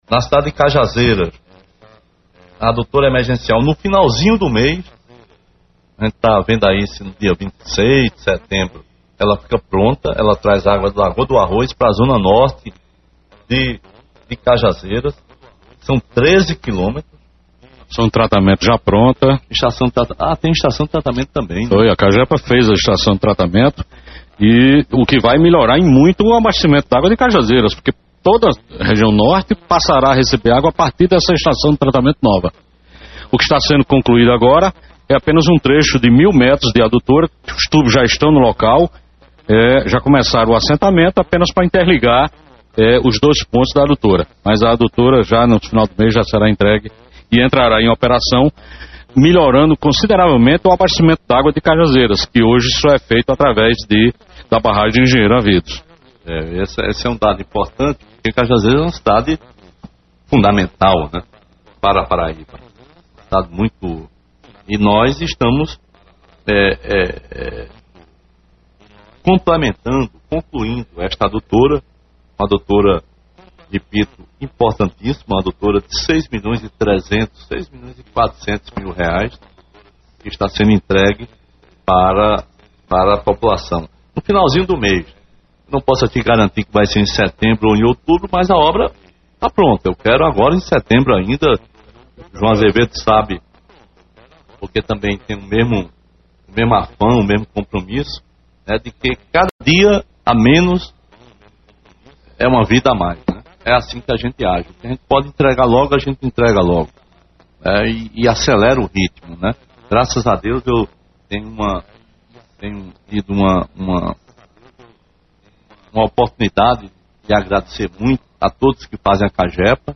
O governador Ricardo Coutinho em seu programa semanal, Fala Governador, confirmou para até o final deste mês(Setembro) a entrega desta obra, tão esperada pela zona norte de Cajazeiras.